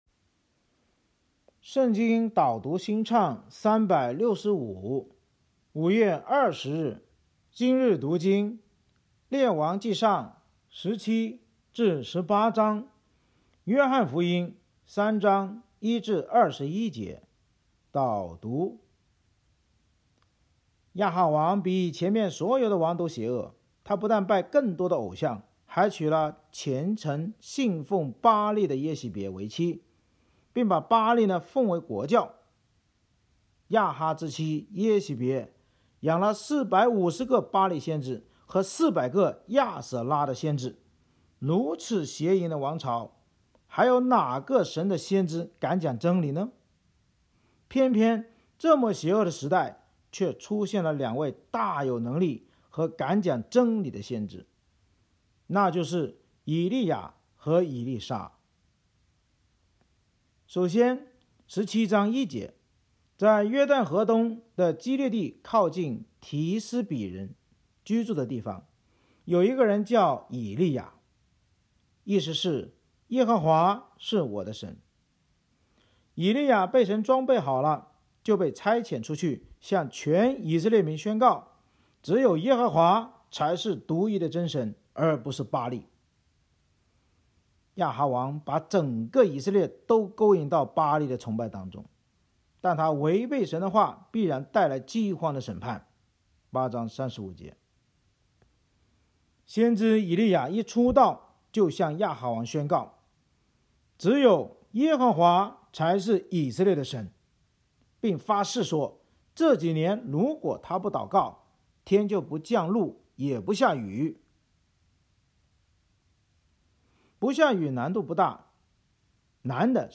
圣经导读&经文朗读 – 05月20日（音频+文字+新歌）